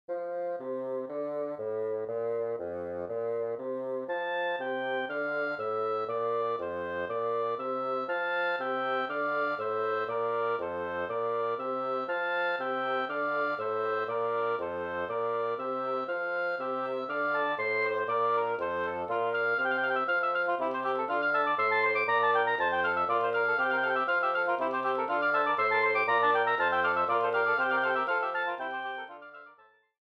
Arreglo para cuarteto
Formación: 3 oboes y 1 corno inglés / fagot